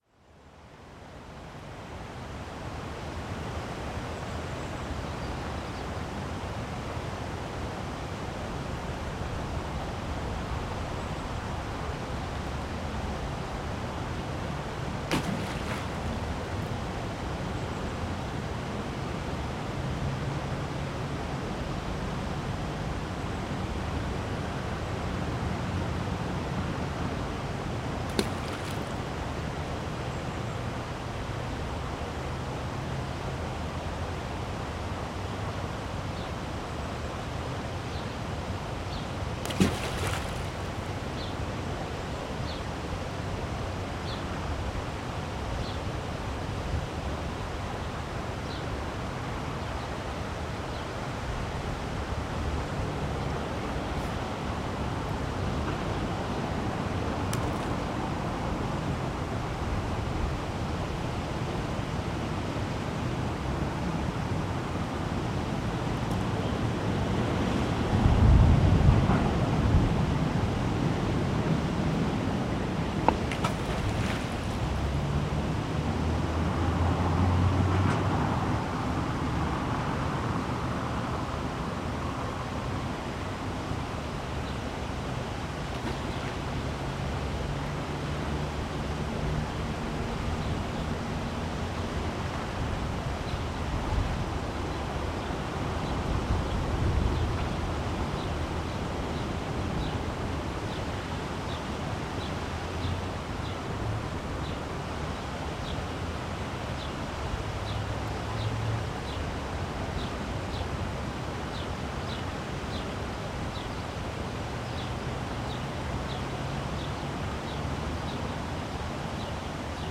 Registo sonoro junto ao rio Pavia, entre o som do trânsito e de umas pedras que são atiradas ao rio. Gravado com Zoom H4.
Tipo de Prática: Paisagem Sonora Rural
Viseu-Rua-do-Coval-Rio-Pavia.mp3